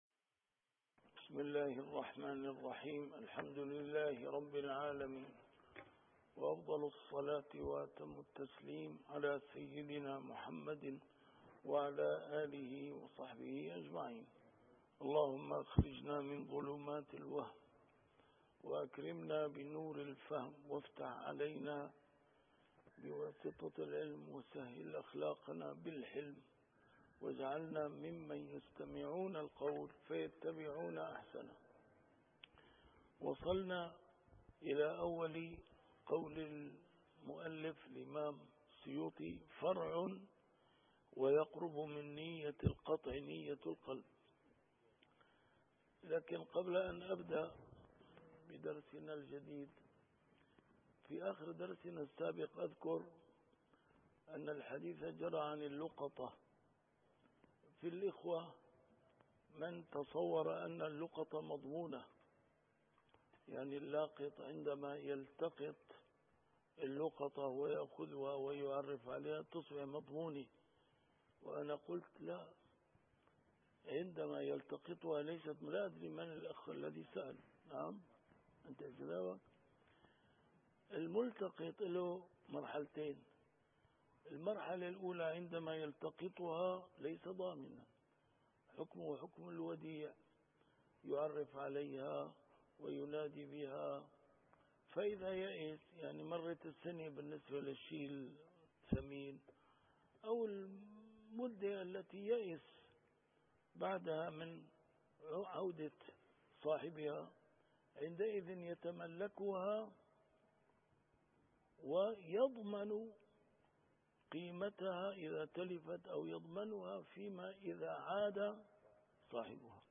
كتاب الأشباه والنظائر للإمام السيوطي - A MARTYR SCHOLAR: IMAM MUHAMMAD SAEED RAMADAN AL-BOUTI - الدروس العلمية - القواعد الفقهية - كتاب الأشباه والنظائر، الدرس الثاني والعشرون: ما ينافي النية